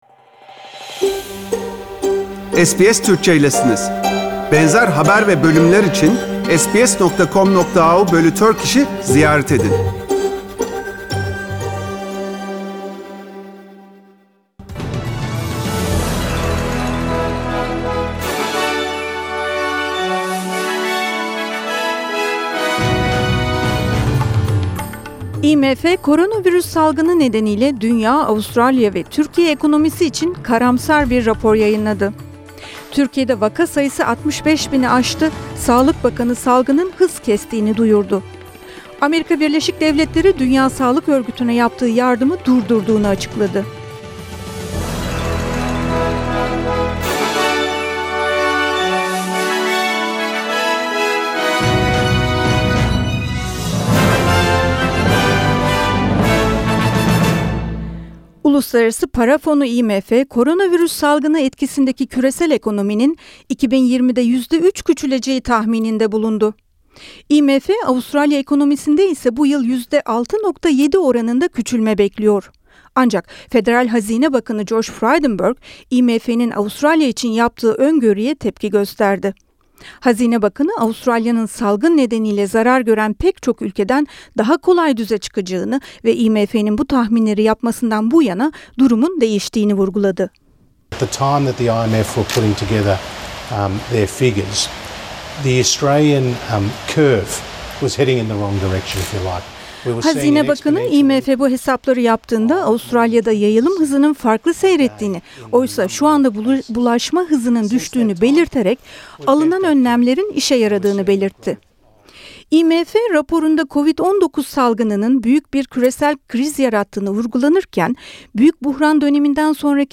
SBS Türkçe Haberler